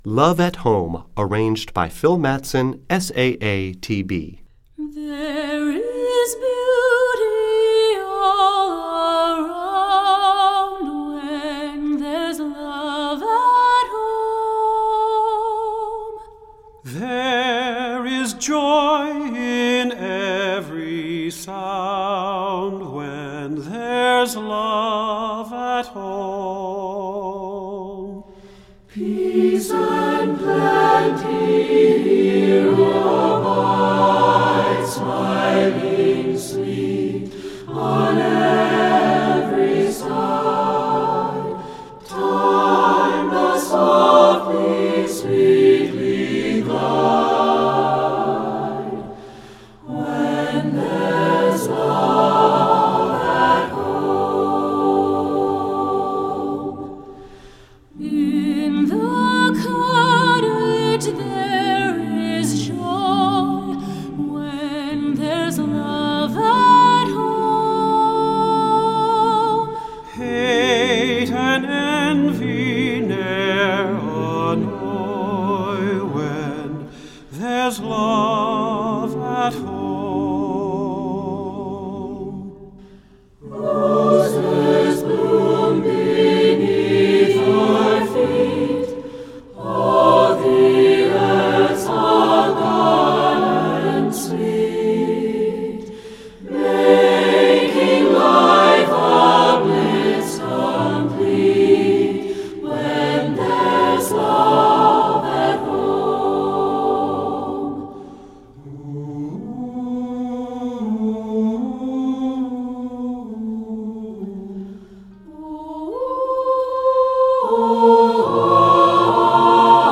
Voicing: SSATB a cappella